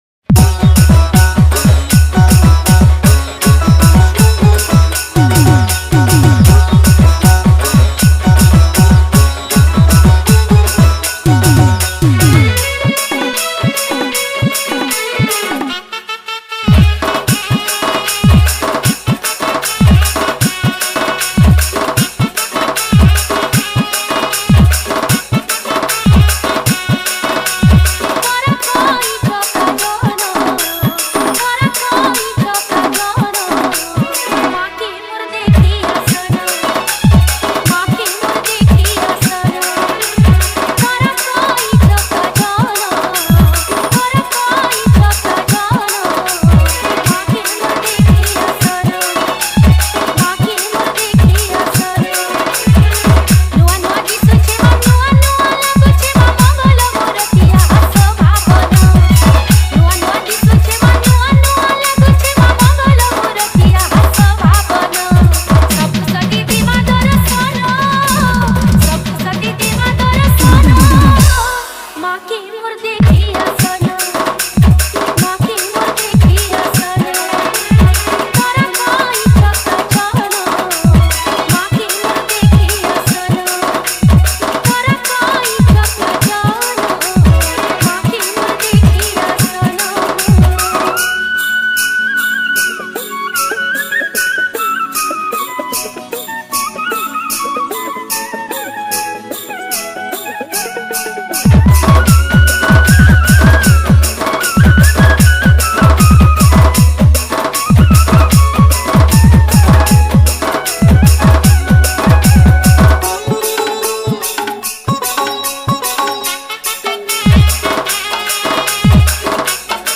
Category:  Sambalpuri Bhajan Dj